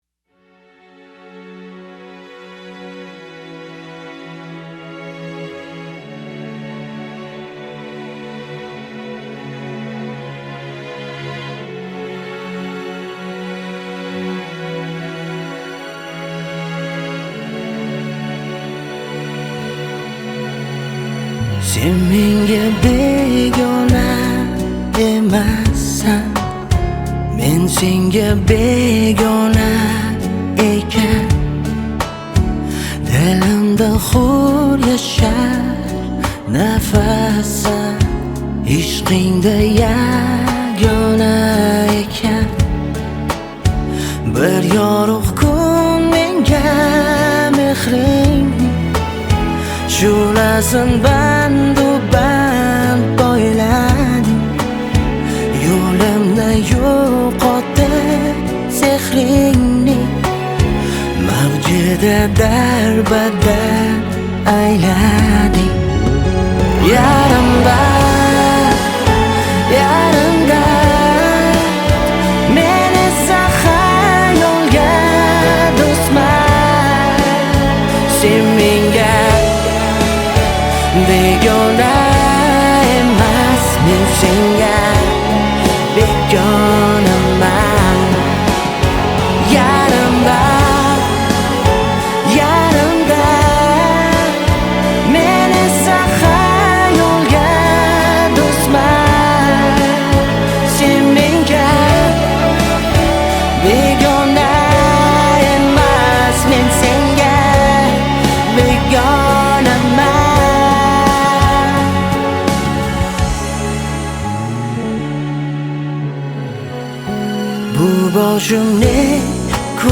Качество: 320 kbps, stereo
Узбекская музыка